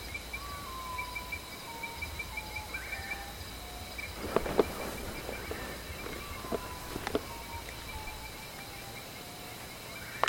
Common Potoo (Nyctibius griseus)
Un ejemplar se escuchaba desde el lodge que esta sobre la ruta.
Condition: Wild
Certainty: Recorded vocal